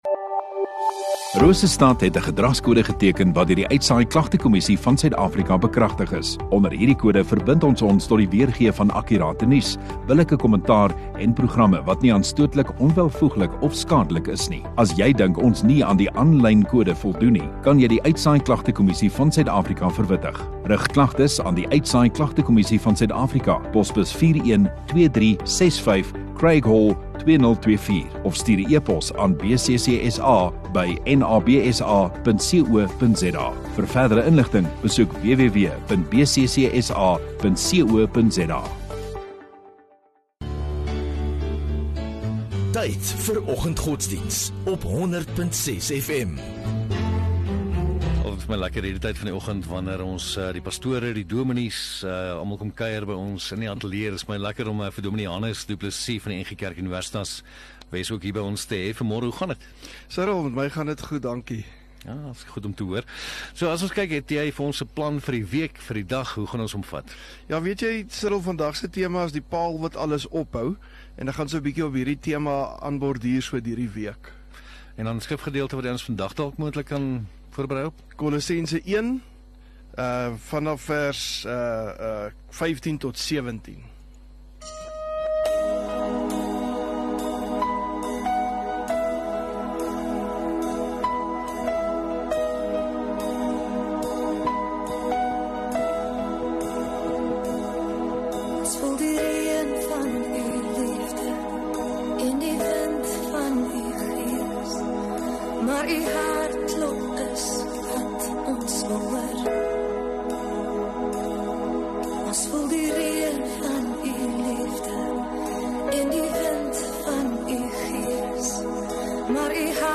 12 Mar Dinsdag Oggenddiens